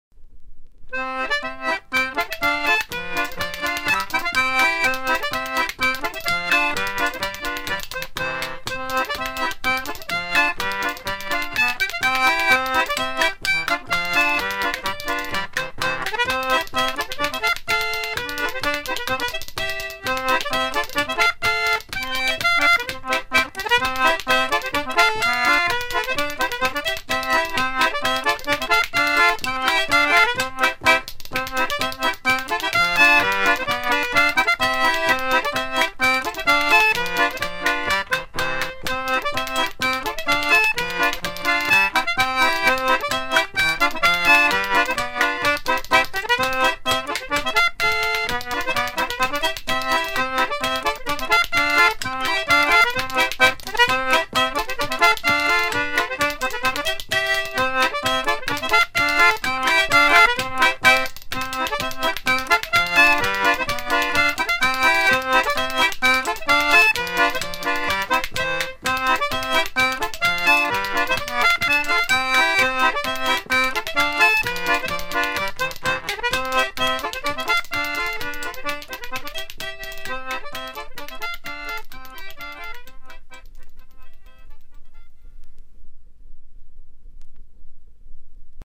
Polka
danse : polka